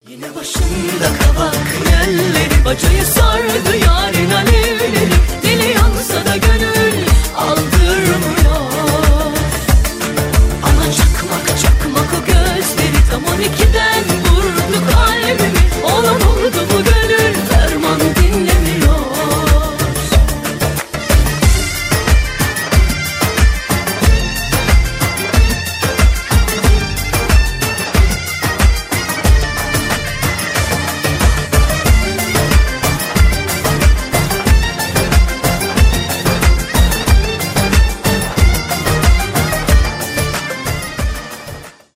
дуэт
поп , танцевальные